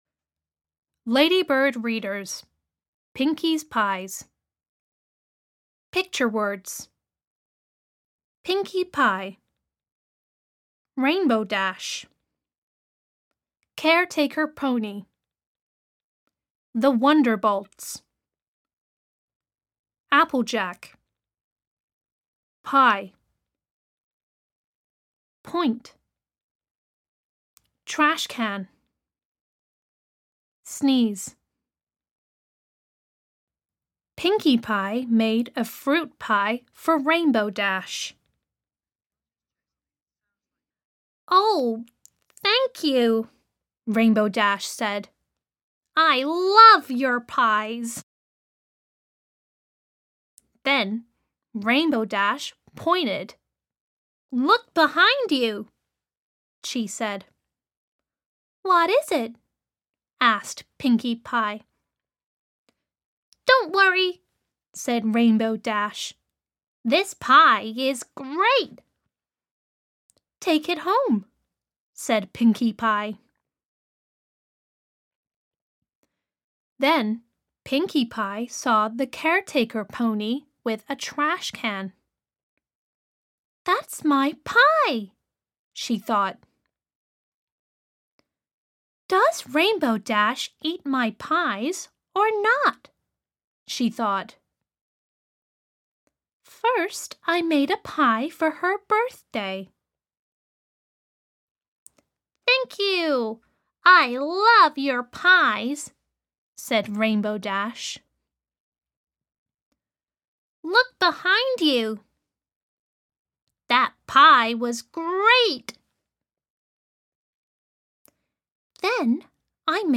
Audio US
Reader - Ladybird Readers